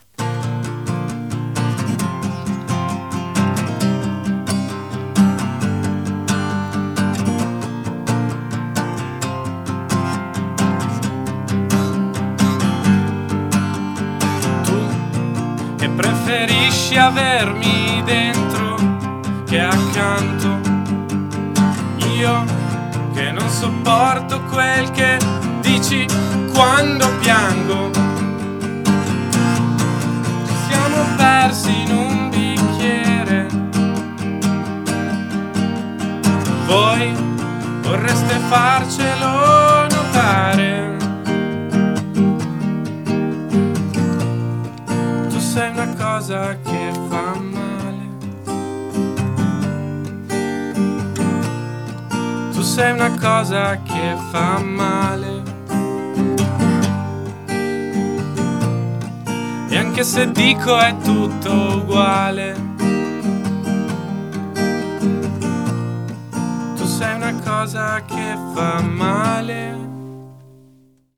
live unplugged